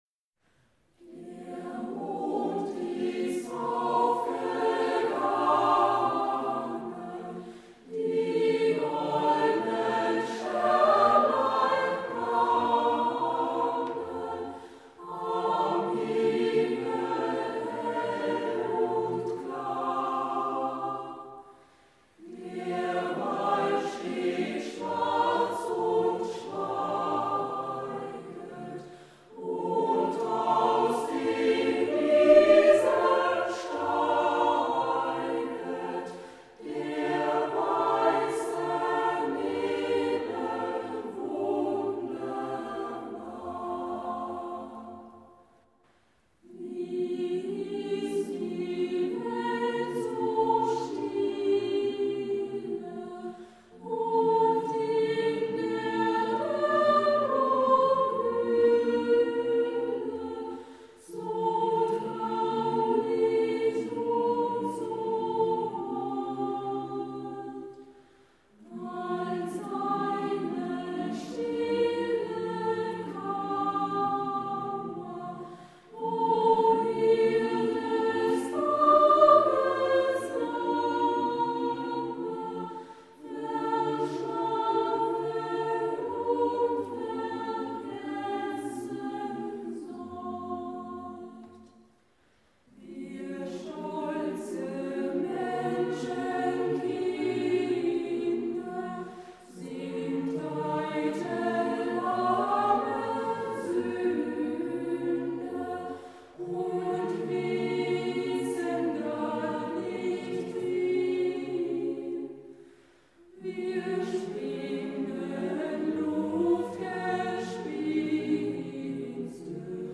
Kammerchor am Kurfürst-Friedrich-Gymnasium Heidelberg